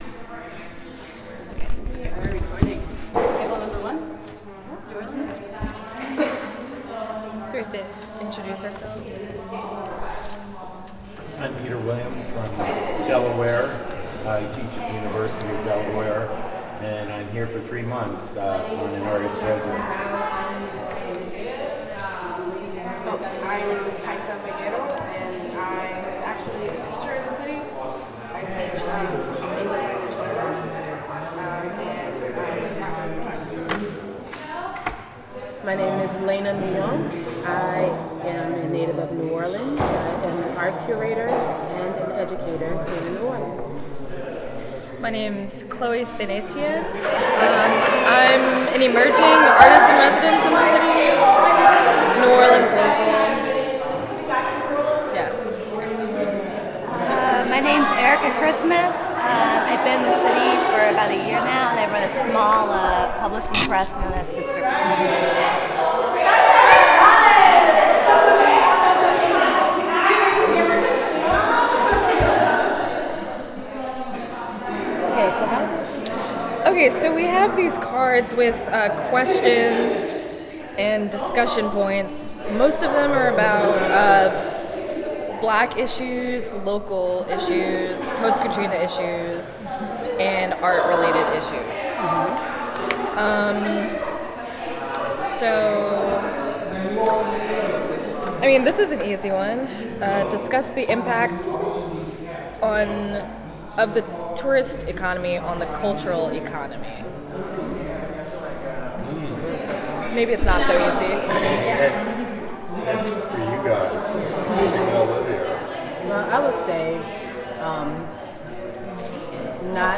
Type sound recording-nonmusical
Genre oral history